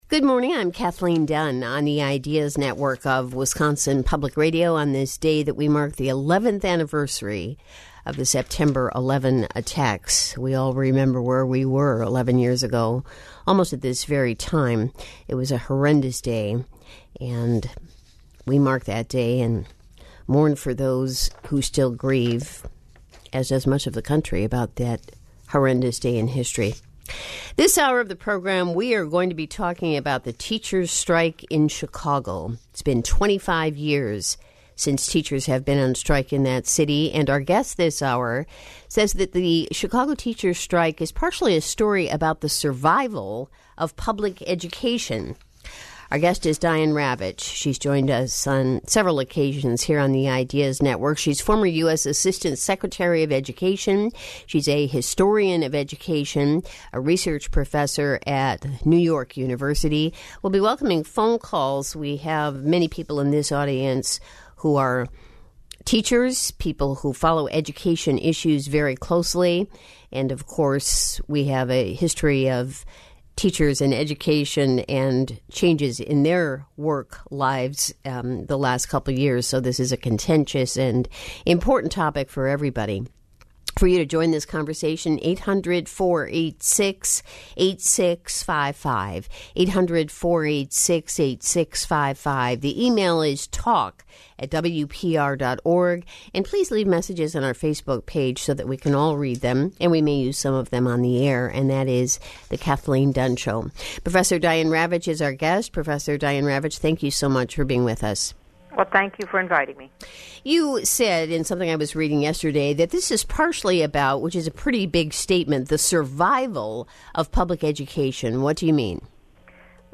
Diane Ravitch Interview